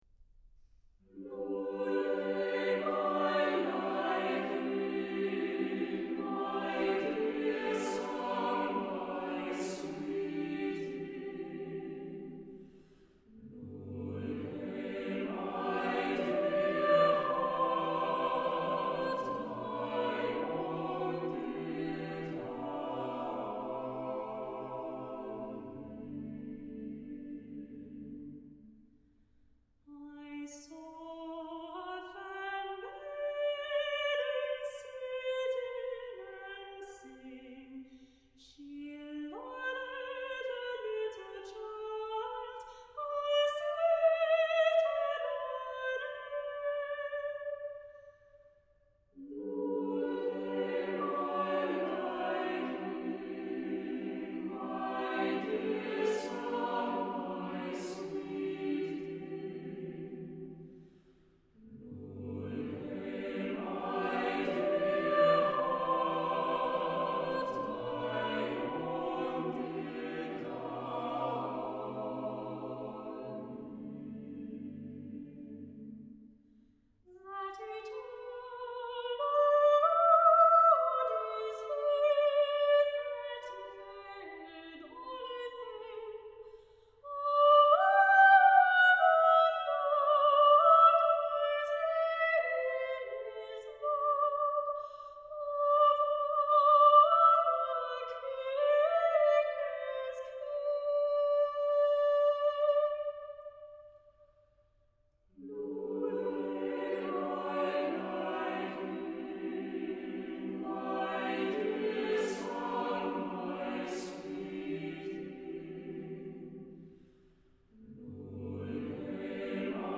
Voicing: SATB a cappella